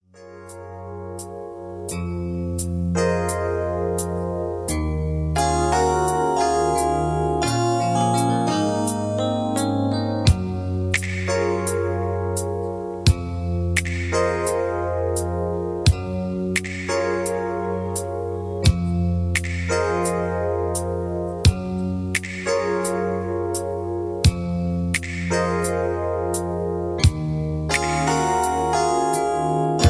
(Version-2, Key-F#) Karaoke MP3 Backing Tracks